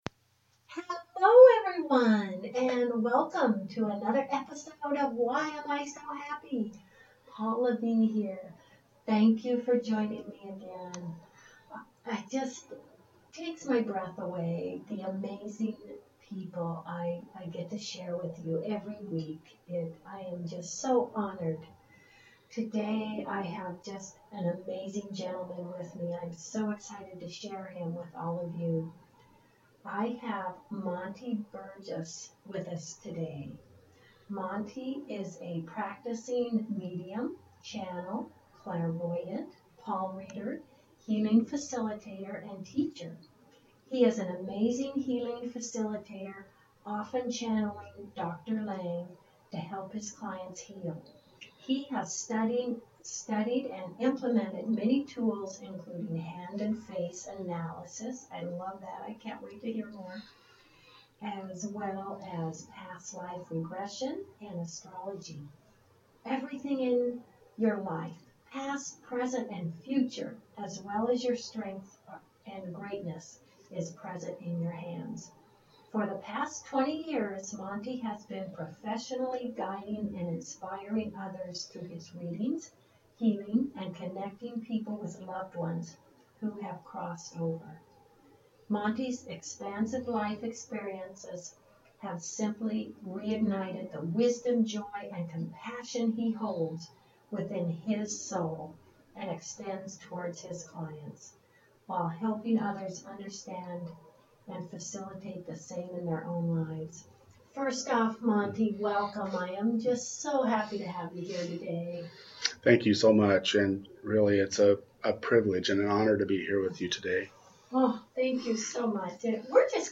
with guest